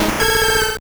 Cri de Méganium dans Pokémon Or et Argent.